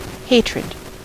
Ääntäminen
IPA: [has]